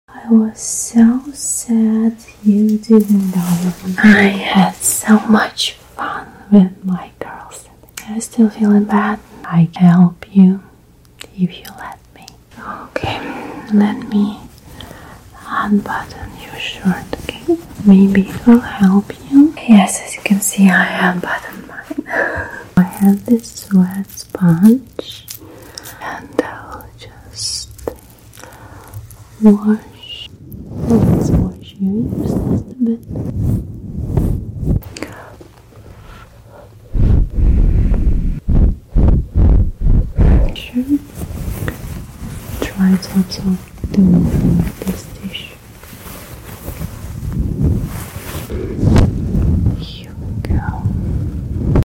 ASMR Your gf will cure sound effects free download